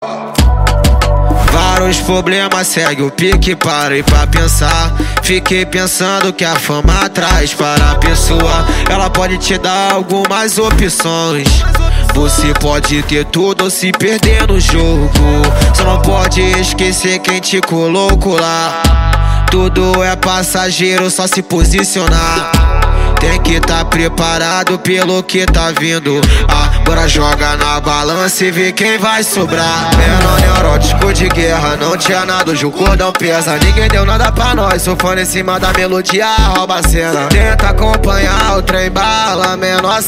Categoria Rap